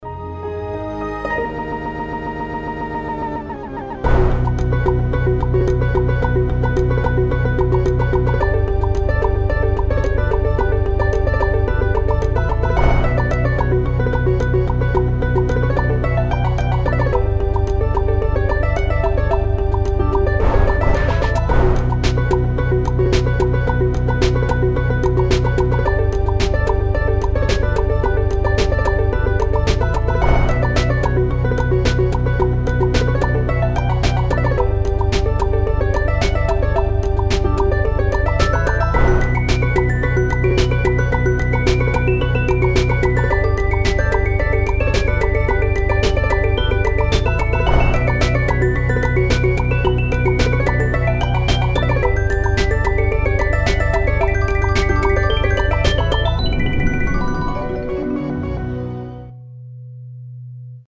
• Качество: 320, Stereo
ритмичные
Electronic
без слов
звонкие